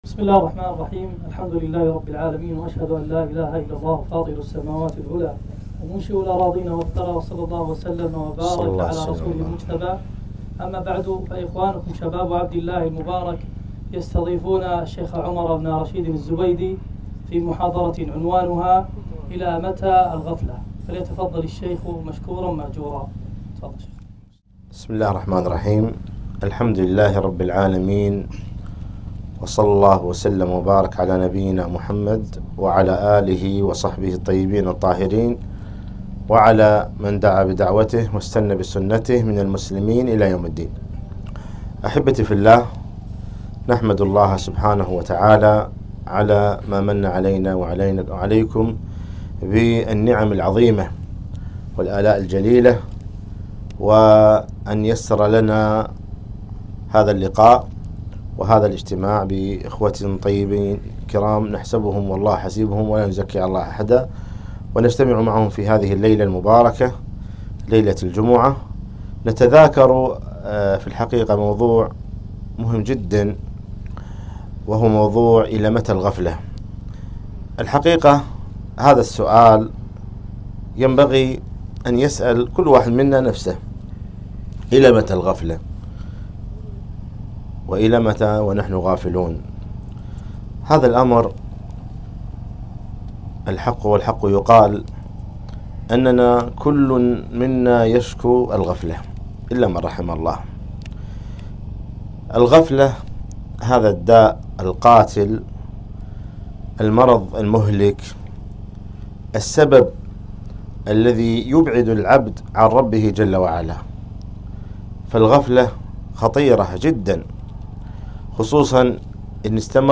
محاضرة - إلى متى الغفلة ؟!